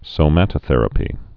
(sō-mătə-thĕrə-pē, sōmə-tə-)